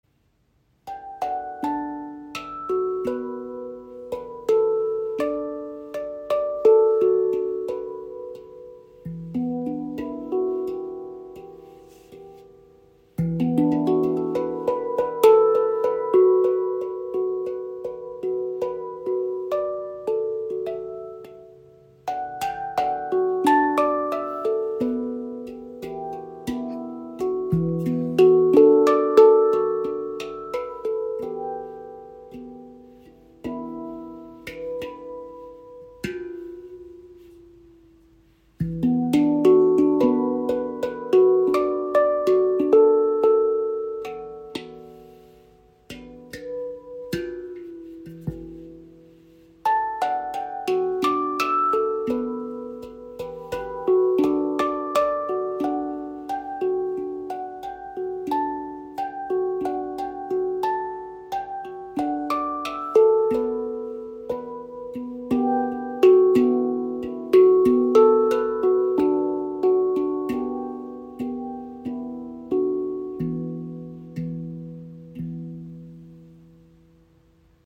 • Icon E Amara – beruhigende Klanglandschaft (E – B D E F# G A B D E F# G A)
• Icon Edelstahl-Handpan – warmer, langer Sustain für meditative Musik
Handgefertigte Edelstahl-Handpan mit offener, ruhiger Stimmung, tiefer Erdung, langem Sustain und fliessendem Klang – ideal für Meditation und Klangreisen.
Die E-Amara-Stimmung umfasst die Töne E – B D E F# G A B D E F# G A und entfaltet eine ruhige, offene Klanglandschaft mit tiefer Erdung und sanfter Weite.
Der Charakter der Stimmung ist ruhig, tragend und ausgewogen – weder melancholisch noch dominant.